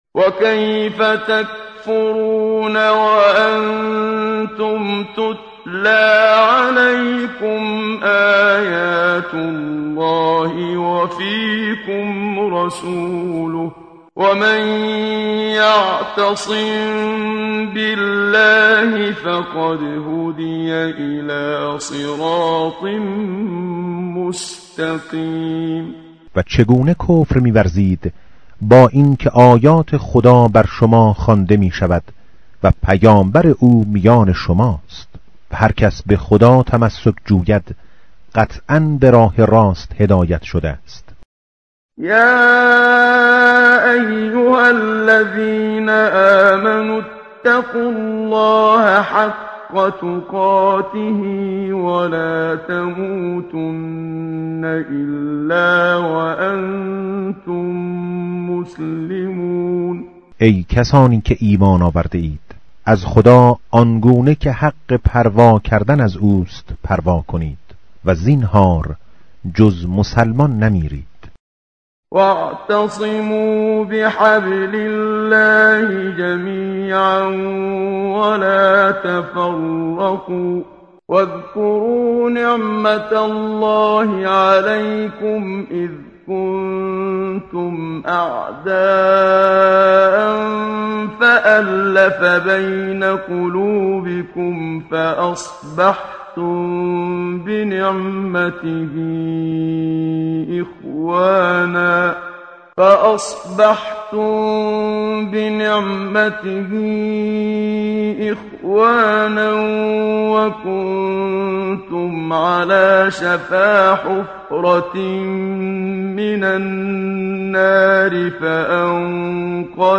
متن قرآن همراه باتلاوت قرآن و ترجمه
tartil_menshavi va tarjome_Page_063.mp3